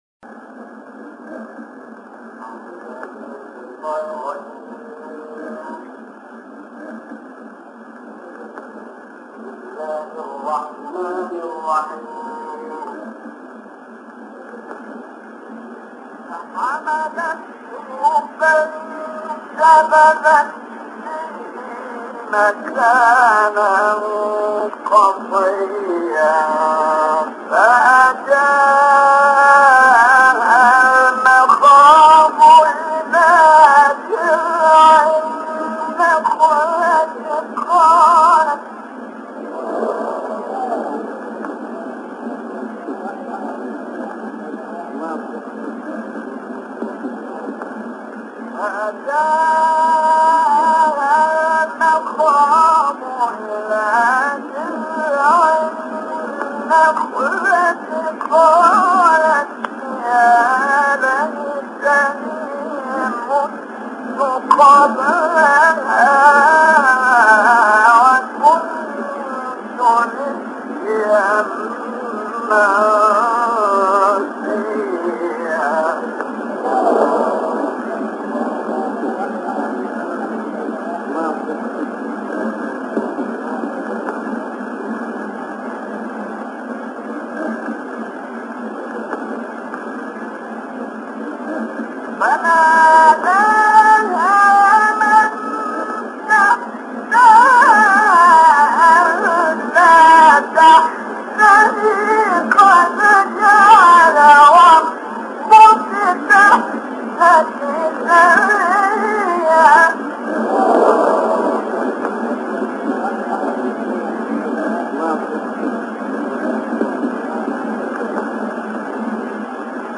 او با اینکه یک نابینا بود، اما با به کارگیری حس درونی خود، نوع خاصی از قرائت قرآن را ارائه کرد، به گونه‌ای که سبک شیخ رفعت متمایز از دیگر قاریان شده است.